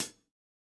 Index of /musicradar/Kit 3 - Acoustic
CyCdh_K3ClHat-02.wav